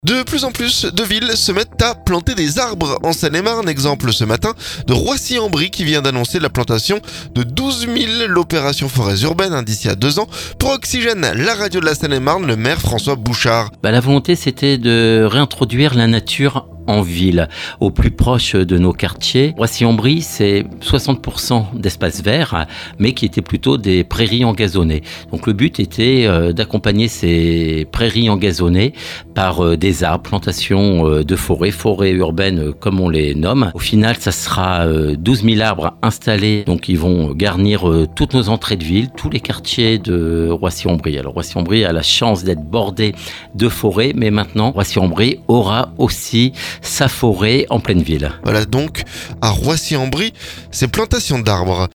Pour Oxygène, la radio de la Seine-et-Marne, le maire François Bouchard.